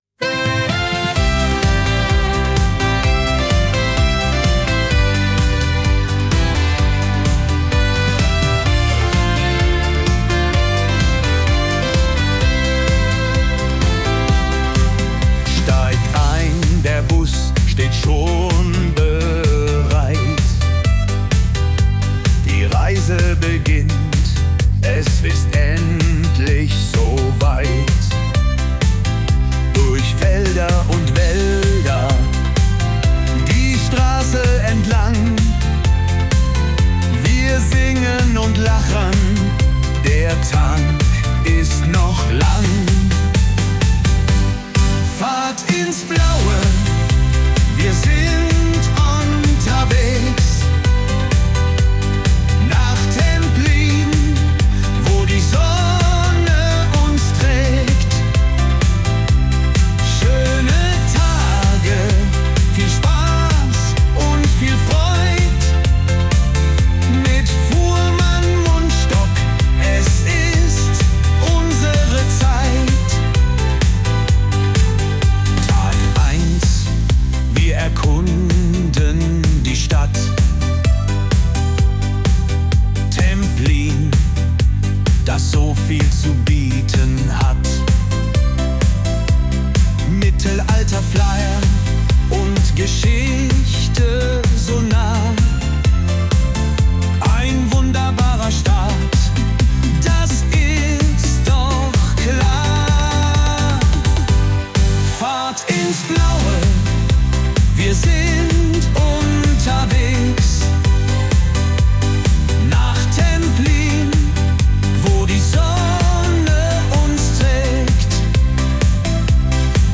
Hier kommt er – der offizielle Song zur Saisoneröffnungstour 2025! 🚌🎶